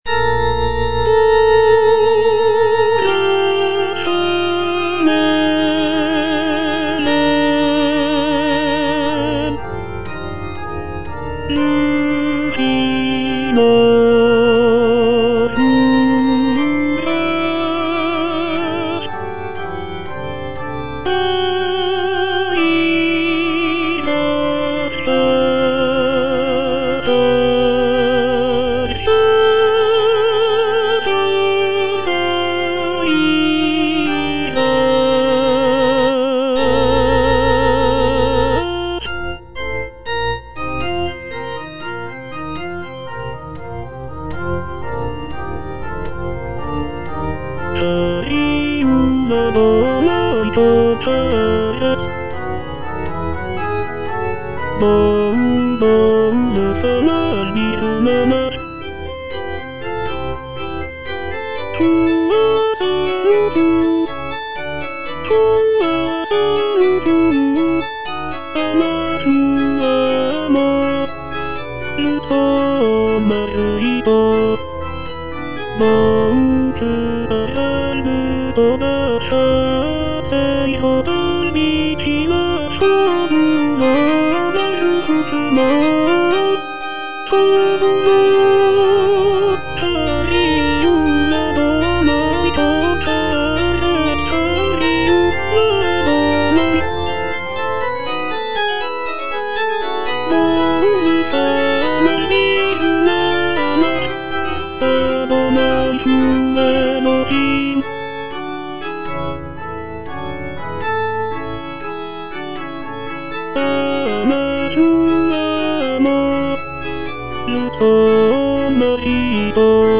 tenor I) (Voice with metronome